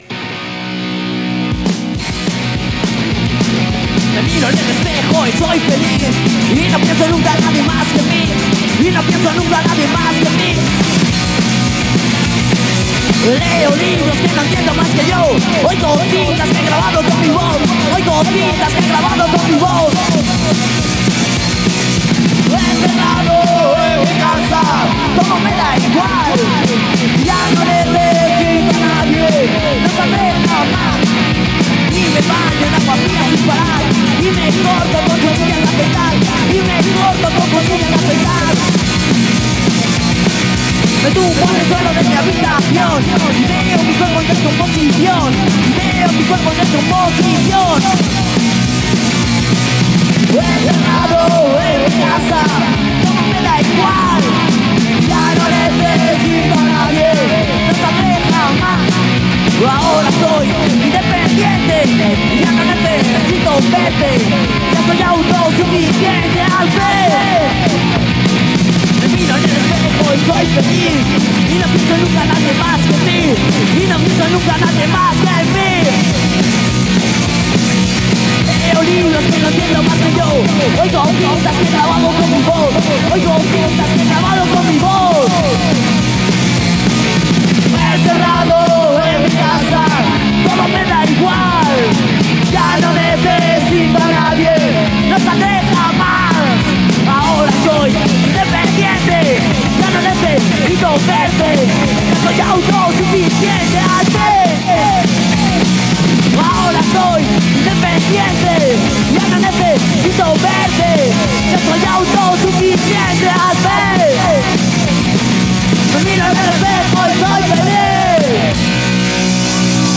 Spanish Punk/Darkwave/Post-Punk band
singer/guitarist